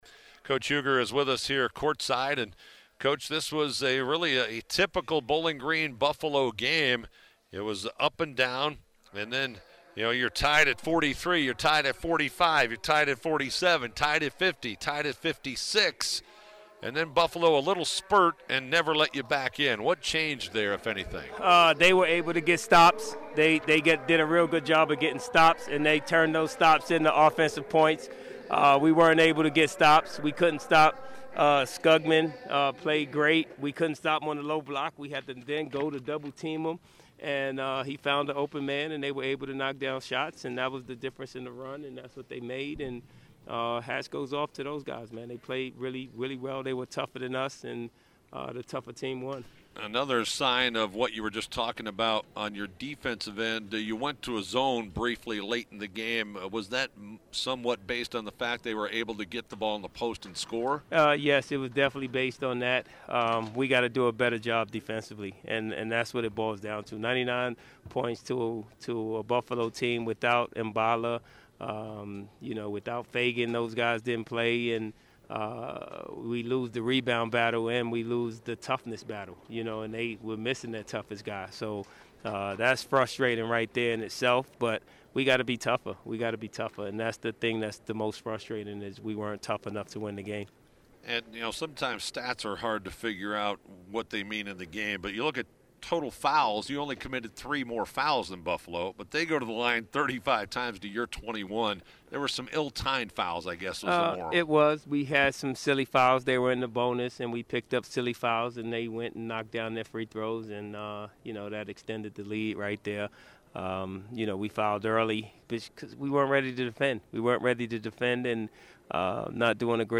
COACH'S QUOTE
Full Postgame Interview